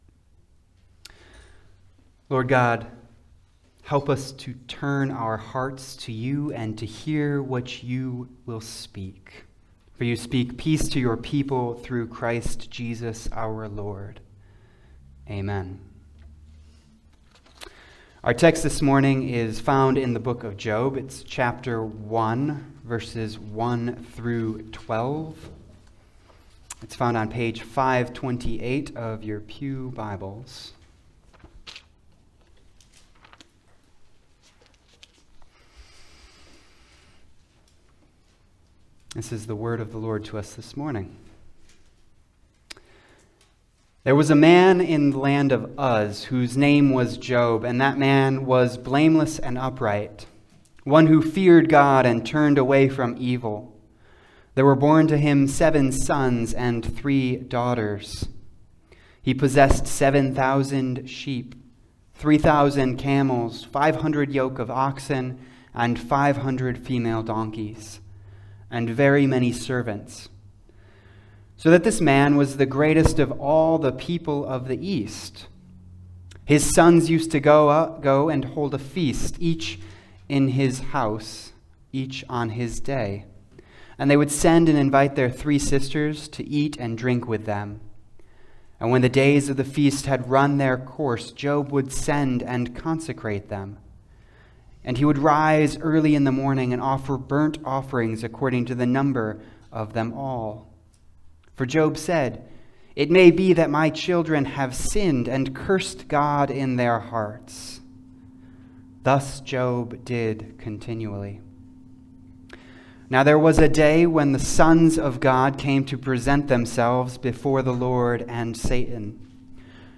Passage: Job 1:1-12 Service Type: Sunday Service